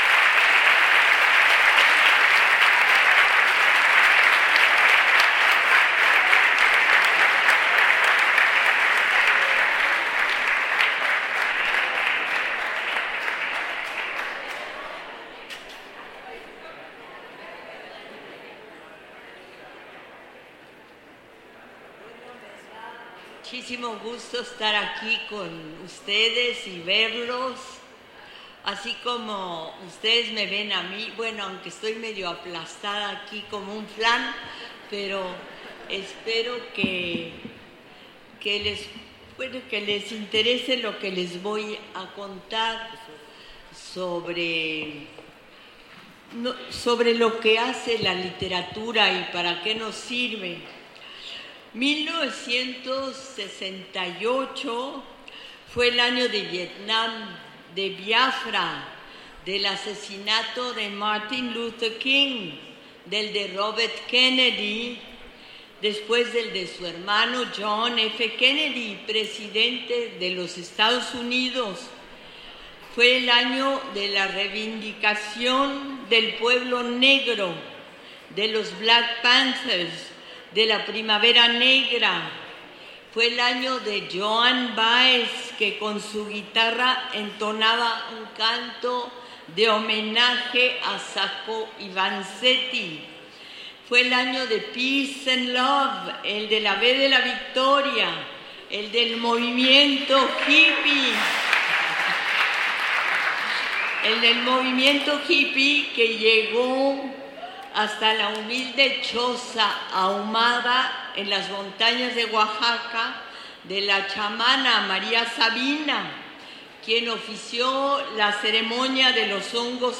Compartimos la charla que ofreció la escritora, periodista y activista social Elena Poniatowska Amor que ofreció a los estudiantes de la Universidad Autónoma de Chiapas.
Lugar: Centro de Convenciones Manuel Velasco Suárez.